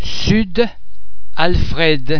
Please be mindful of the fact that all the French sounds are produced with greater facial, throat and other phonatory muscle tension than any English sound.
The French [d] and [dd] are normally pronounced a single [d] sound as in the English words addres, addition, dad, drive etc.